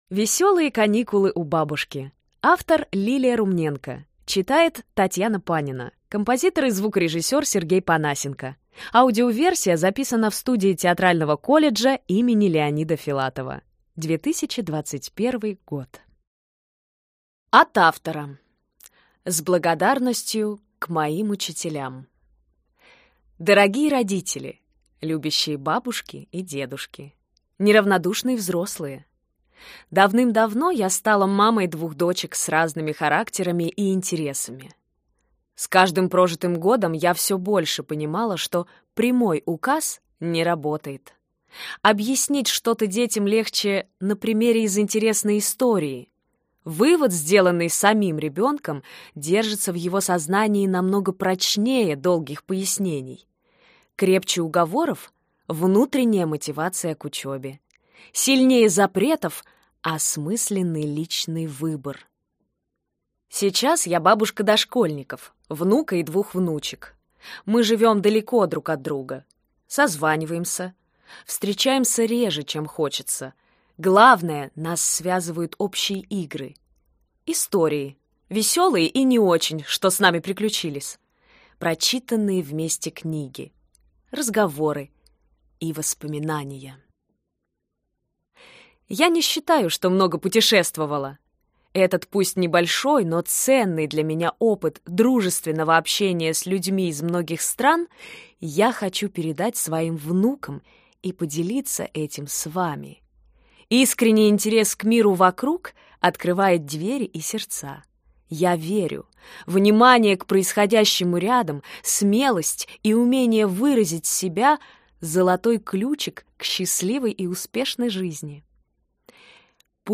Аудиокнига Веселые каникулы у бабушки. Истории о детях и собаках, коте и хомяке, воронах, мангустах и злом змее, или Как жить в согласии | Библиотека аудиокниг